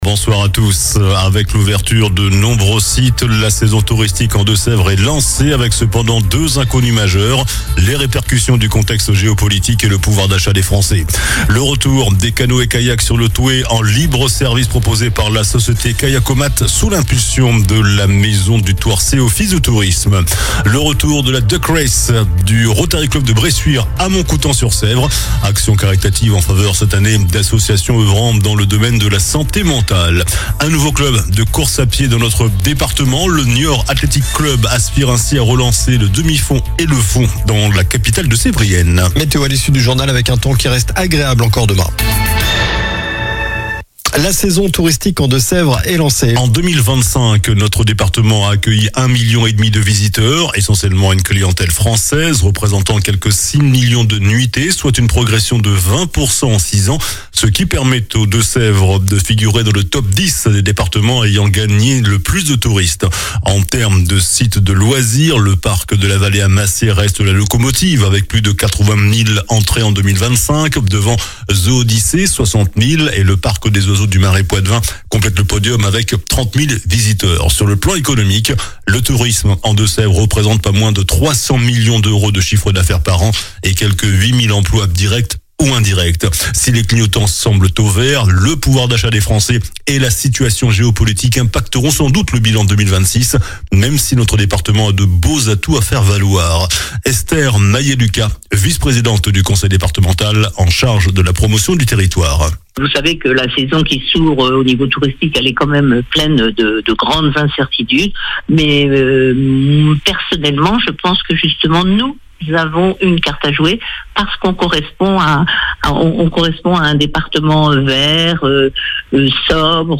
JOURNAL DU JEUDI 09 AVRIL ( SOIR )